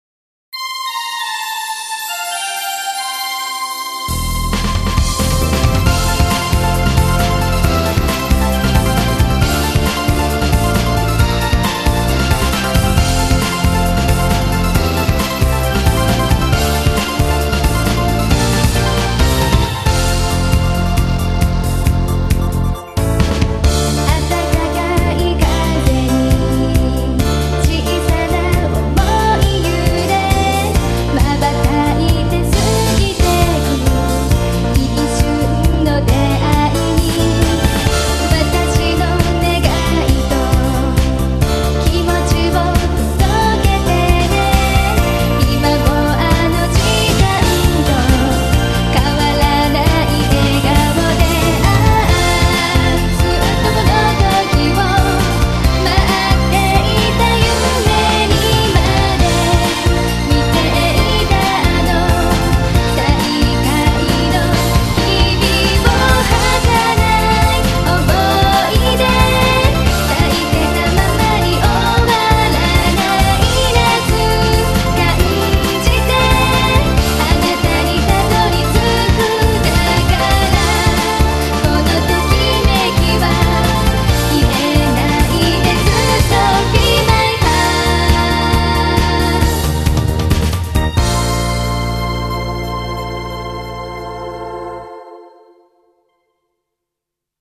◆ 歌モノ ◆
そのop ver.なので、イントロ→１コーラス→エンディングと構成短いやつです。
もちギャルゲーポップさ（ぉ）。